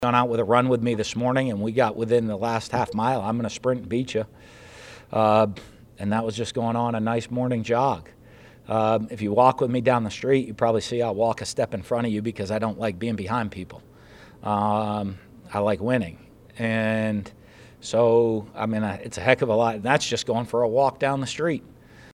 After a difficult loss on the road against Kentucky, Florida football’s head coach, Dan Mullen, was fed numerous questions about this game in a recent press conference.